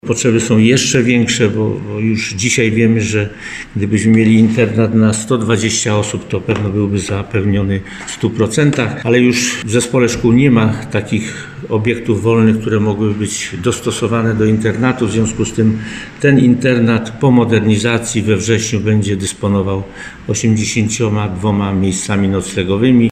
Przy szkole powstanie 20 nowych miejsc – mówi starosta Marek Kwiatkowski.
31starosta.mp3